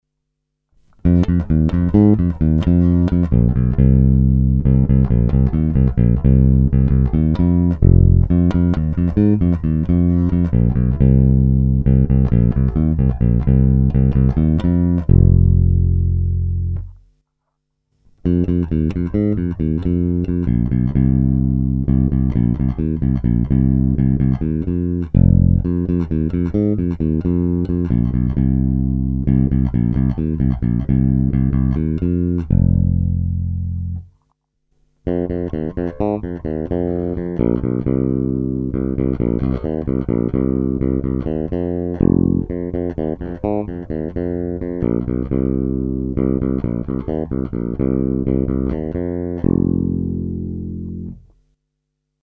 Naprosto typický Jazz Bass, ve zvuku hodně vnímám rezonanci samotného nástroje a desítky let jeho vyhrávání.
Není-li uvedeno jinak, následující nahrávky jsou vyvedeny rovnou do zvukovky, s plně otevřenou tónovou clonou a jen normalizovány, jinak ponechány bez úprav.